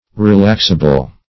Relaxable \Re*lax"a*ble\ (-?-b'l), a. Capable of being relaxed.